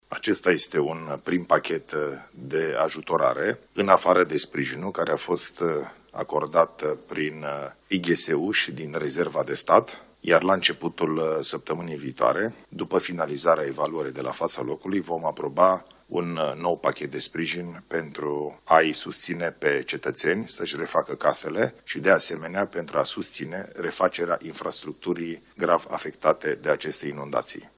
Ilie Bolojan spune că încă un pachet de ajutorare va fi adoptat săptămâna viitoare